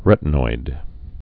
(rĕtn-oid)